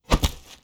Close Combat Attack Sound 23.wav